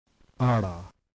[ ɽ ]
U027D Retroflex tap.
U027D_retroflex_r.mp3